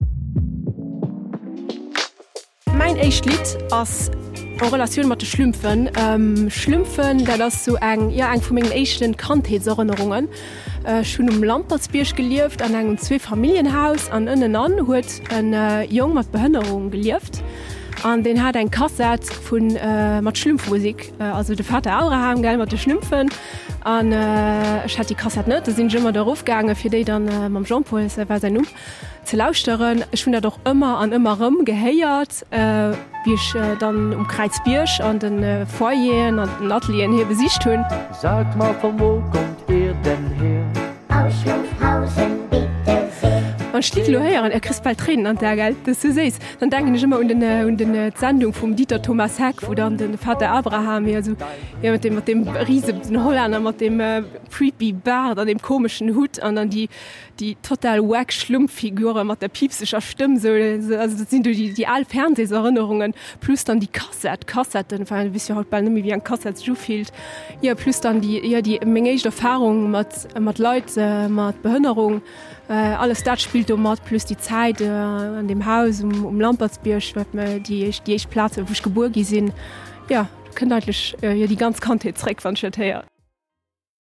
En Interview-Format, dat och emol aner Säite vun den Invitéen ervirbréngt.
“Hannert der Fassad” ass eng Mëschung aus Talk a Musek. E bësse wéi Radio, just besser.